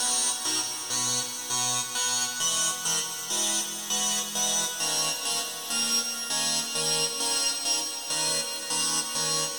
• Lazy Shimmer Texture 100 bpm.wav
Lazy_SHimmer_Texture_100_bpm__6xm.wav